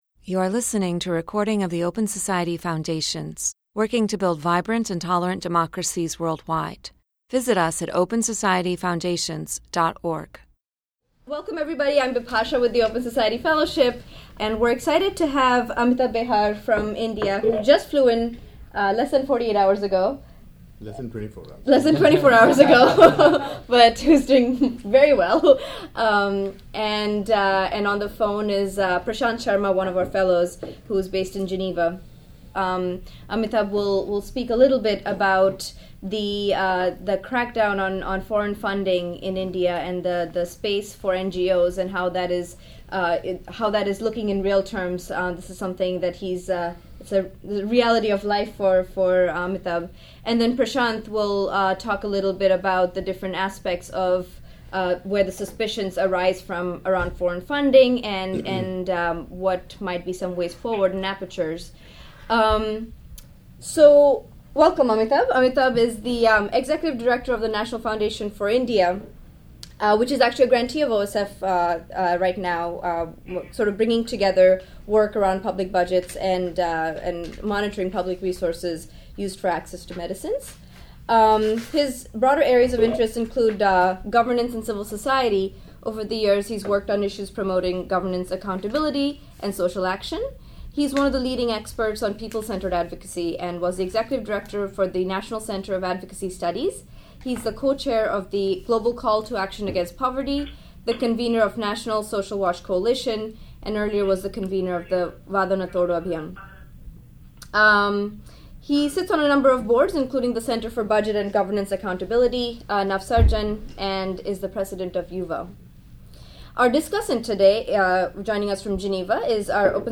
This discussion explores the government’s increasing regulation of nongovernmental organizations and its ramifications for civil society.